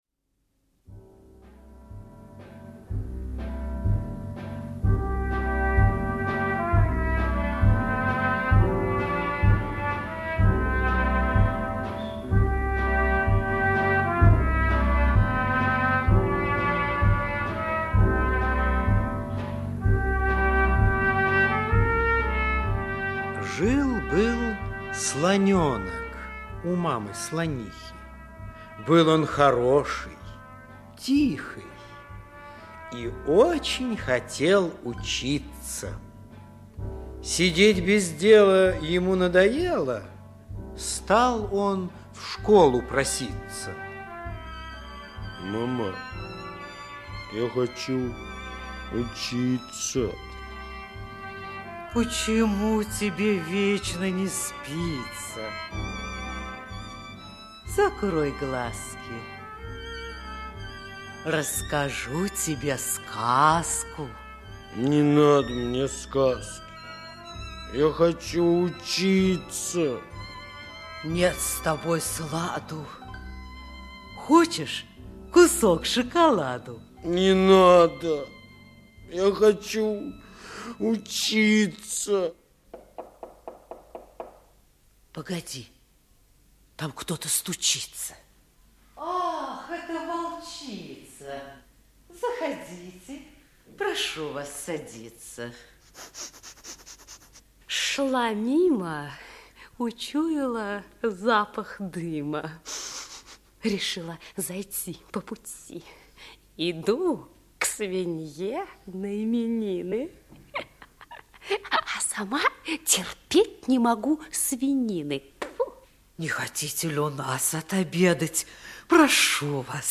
Слоненок пошел учиться - аудиосказка Самойлова - слушать онлайн